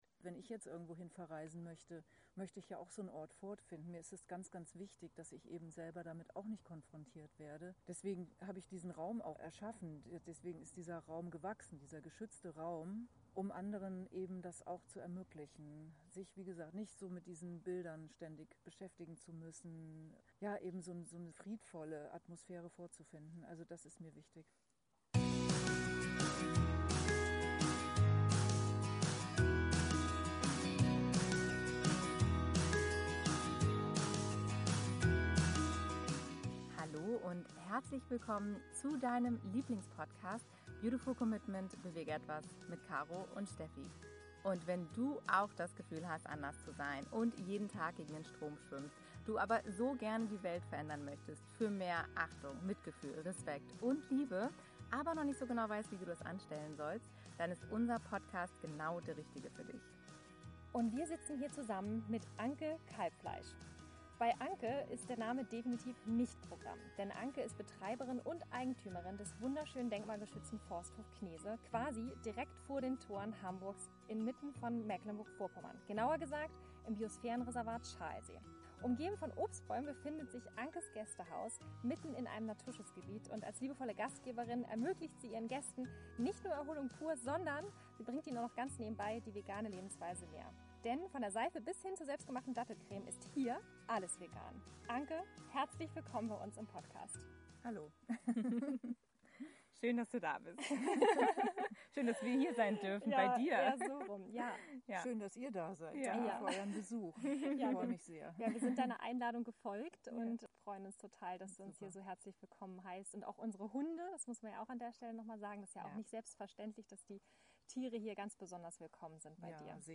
87 Urlaub für die Seele - Interview Special